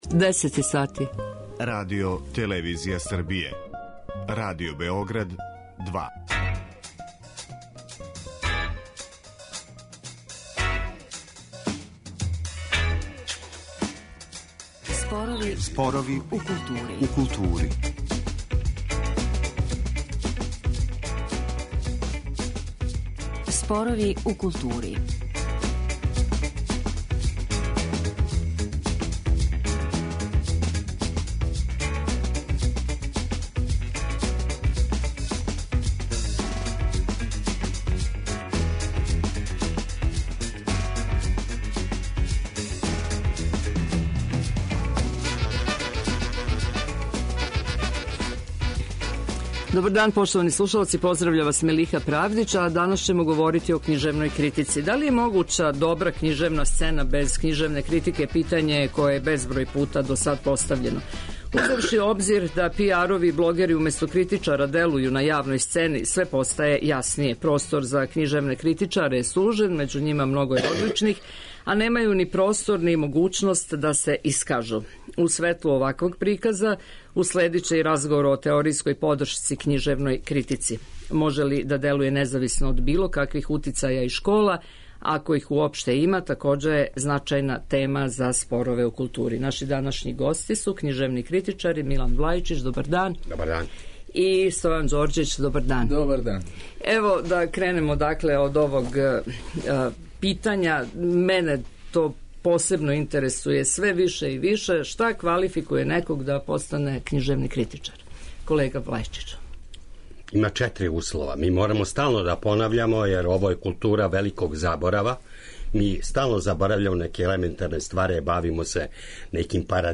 У светлу оваквог приказа уследиће и разговор о теоријској подршци књижевној критици.